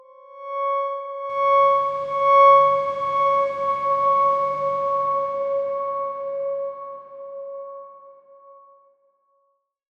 X_Darkswarm-C#5-pp.wav